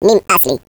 Add hebrew voices